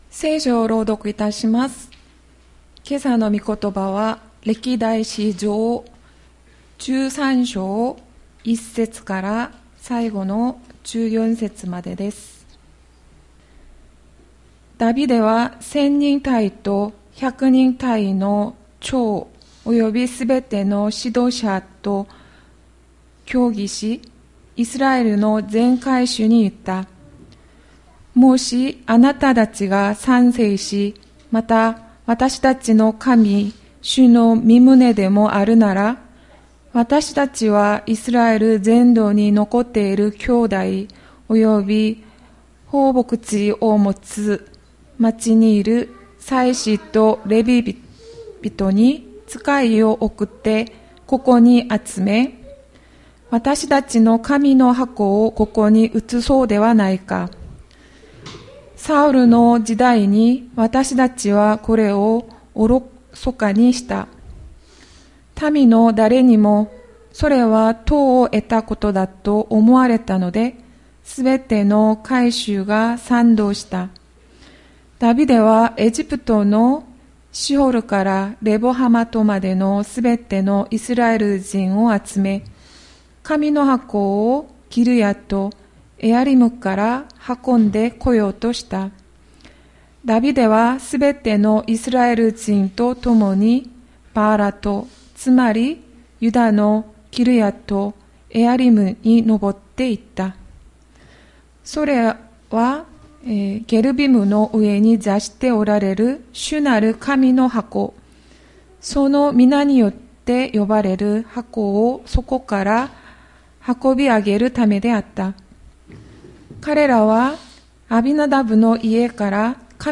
千里山教会 2022年10月30日の礼拝メッセージ。